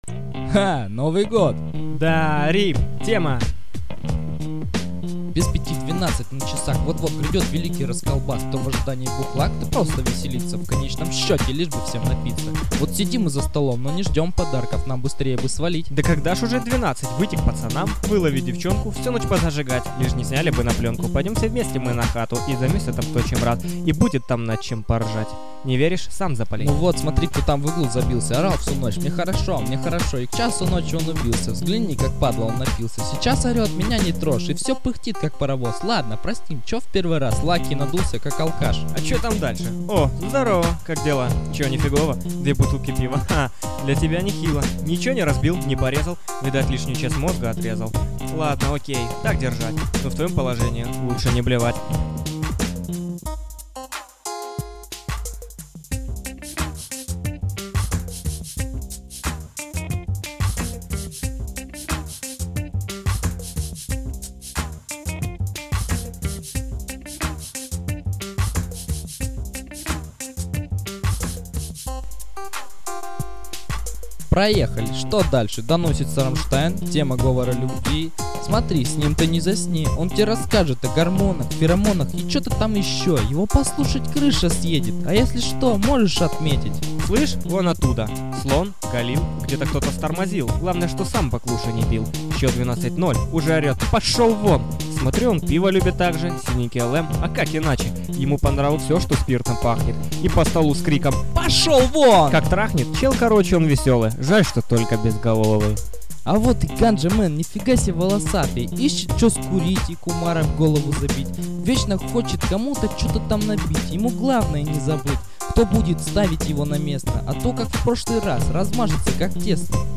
реп группы